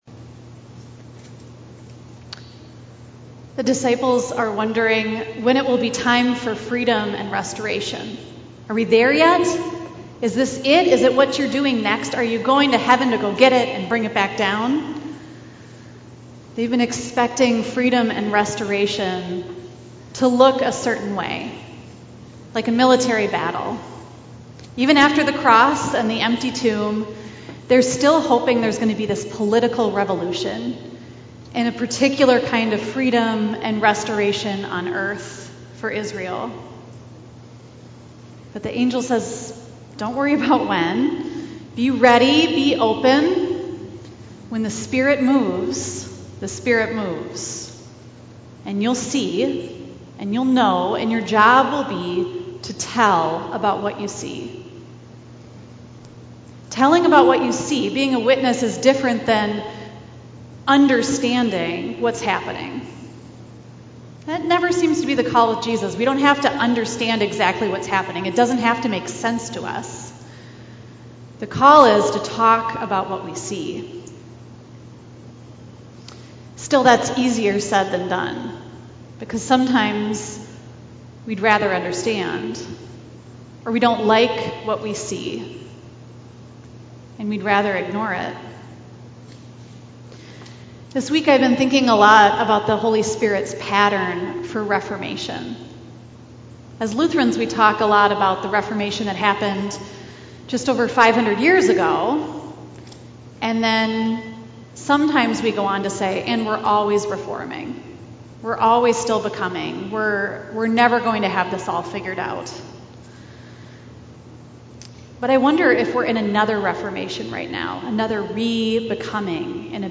Minneapolis Livestream · Sunday, May 16, 2021 10:15 am